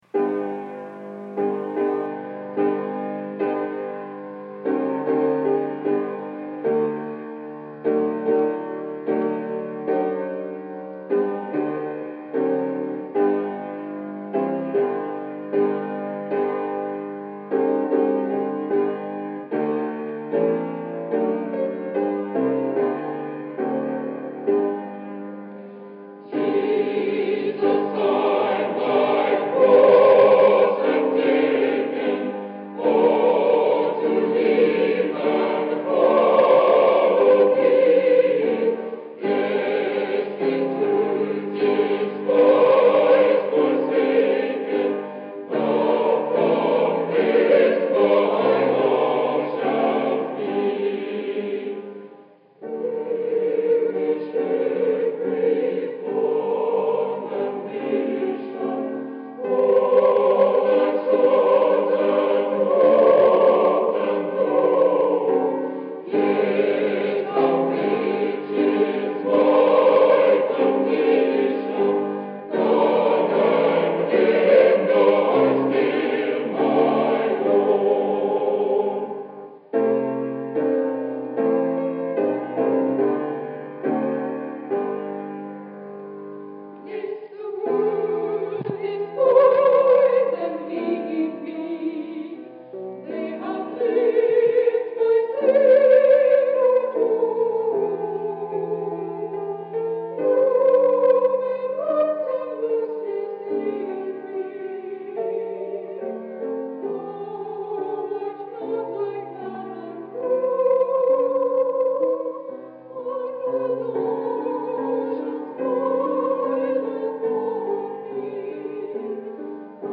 Bethany Nazarene College A Cappella Choir Annual Tour 1962-1963
This is a direct-to-disc recording of the Bethany Nazarene College A Cappella Choir Annual Tour from 1962-1963 year.